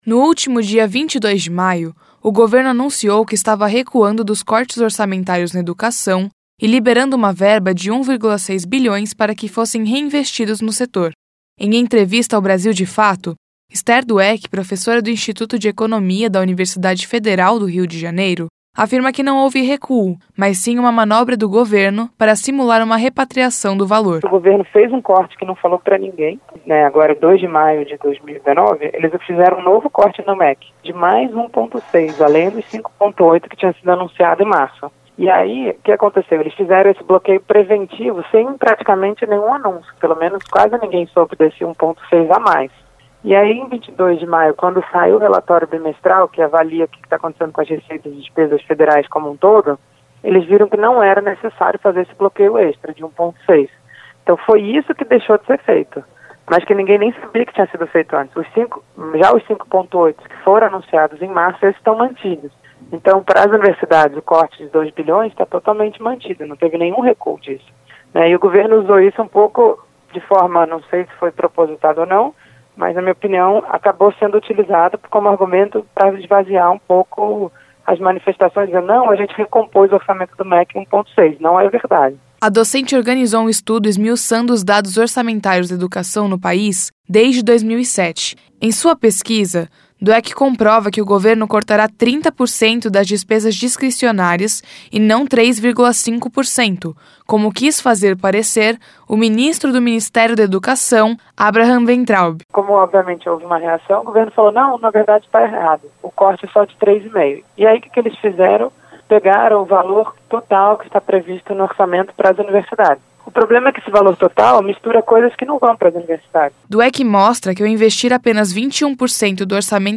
Em entrevista ao Brasil de Fato